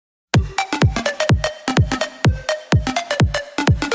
鼓点短信音效 分类：短信铃声 (MP3)点此下载(62KB) (M4R)点此下载(65KB)